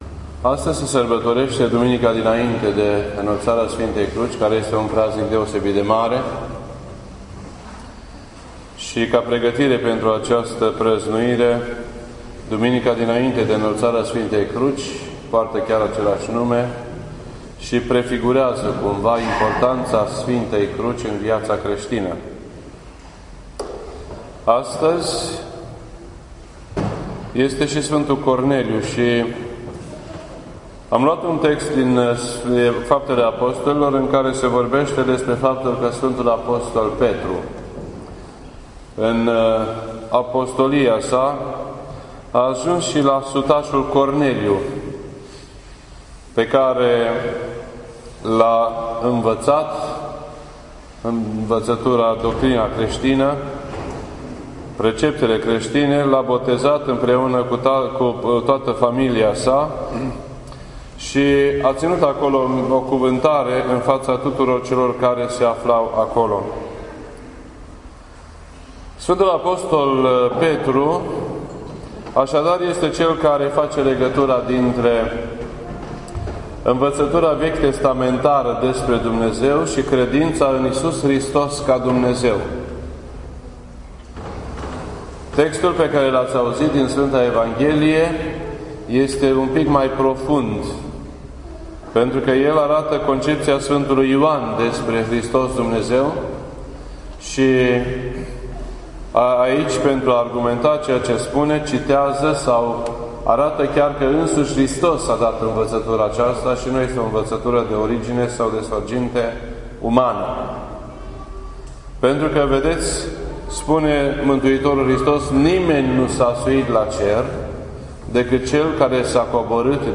This entry was posted on Sunday, September 13th, 2015 at 6:02 PM and is filed under Predici ortodoxe in format audio.